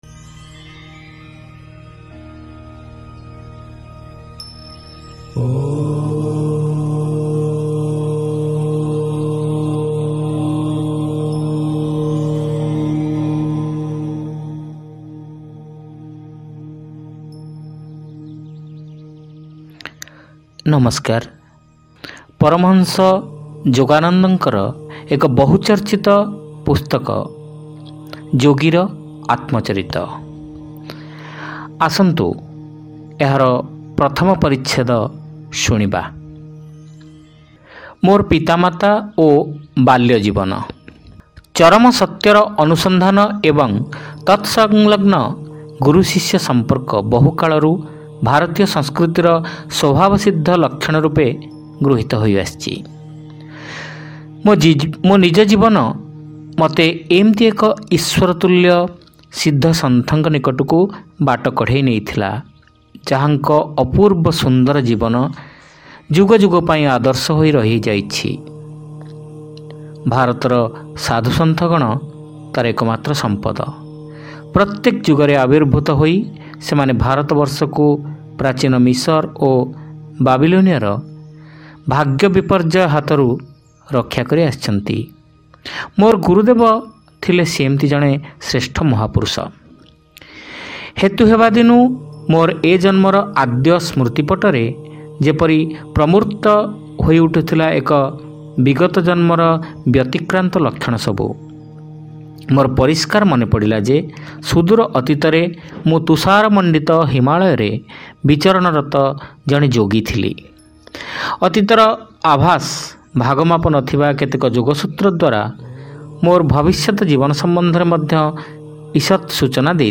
ଶ୍ରାବ୍ୟ ଗଳ୍ପ : ମୋର ପିତାମାତା ଓ ବାଲ୍ୟ ଜୀବନ-ଯୋଗୀର ଆତ୍ମଚରିତ